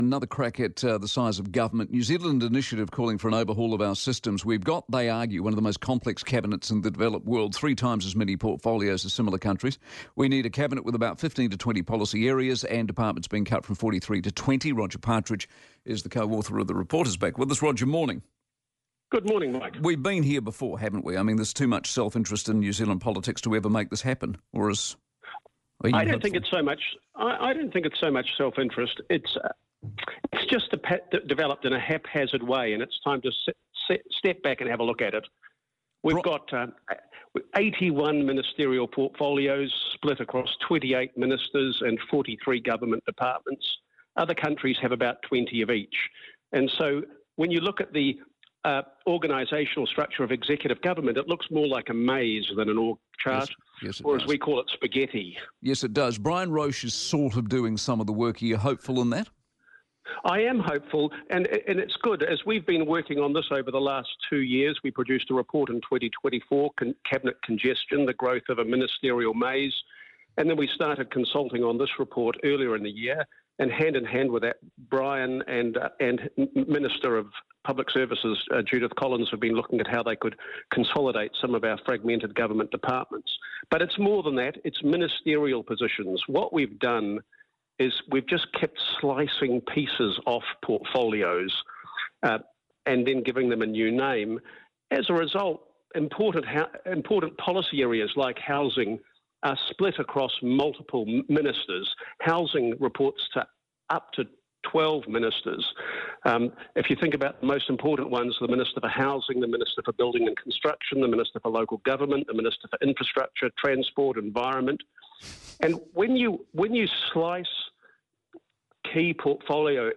Mike Hosking